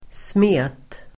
Uttal: [sme:t]